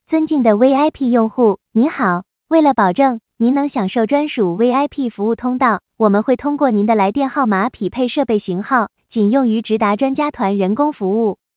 peacock.wav